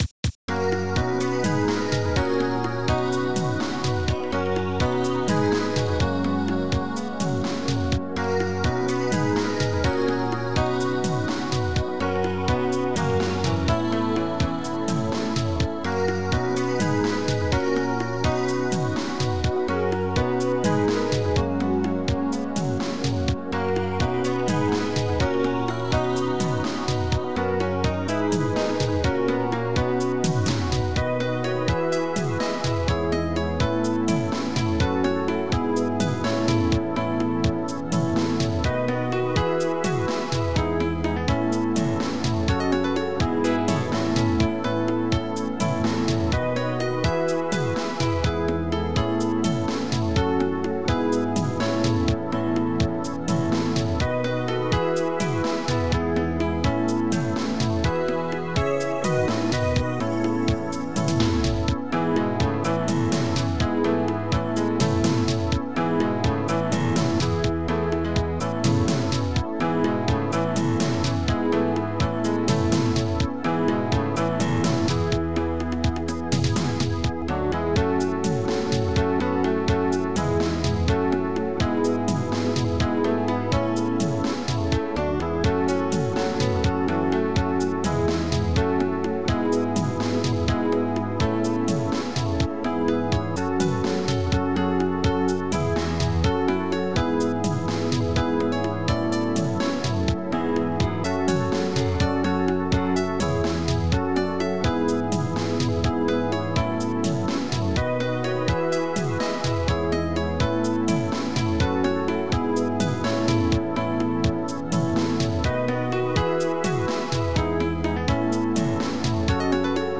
PIANO 01
KICK DRUM 08
SHAKER 00
BONGO(ECHO) 01
WARMKEYS 00
SAX 00